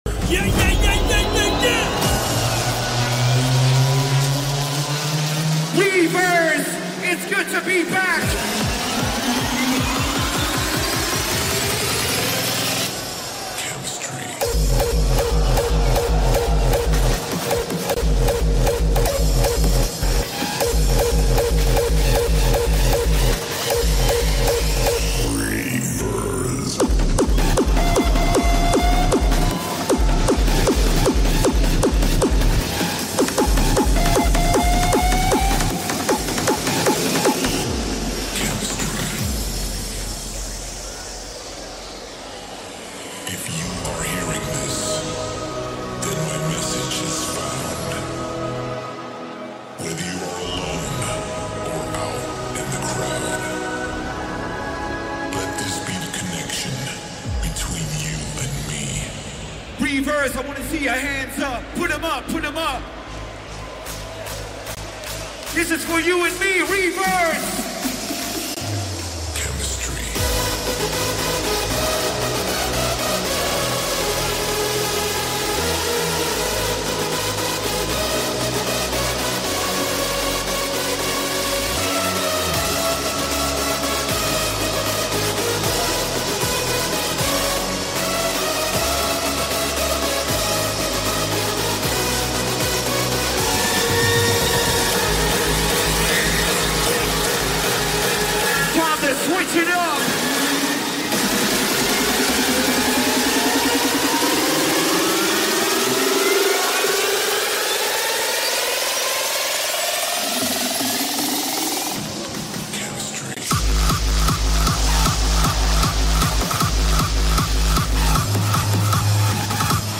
Also find other EDM Livesets, DJ Mixes and Radio
Liveset/DJ mix